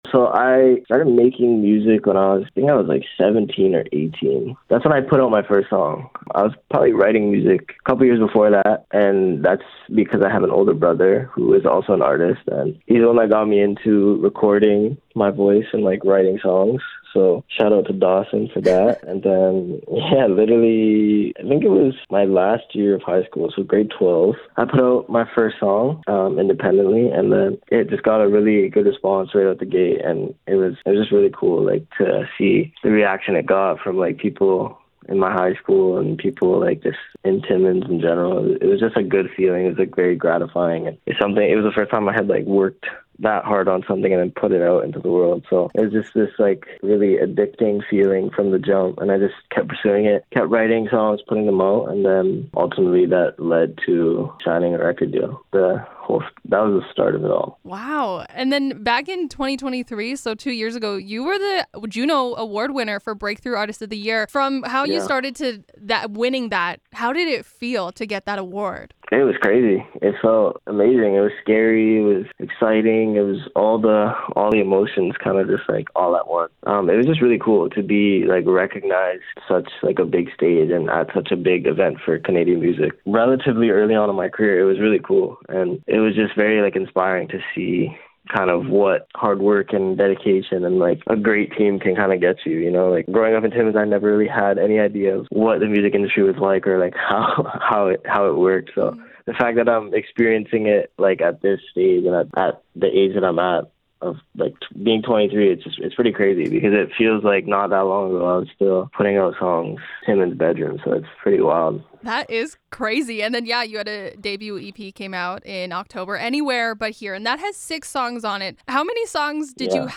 preston-pablo-interview.mp3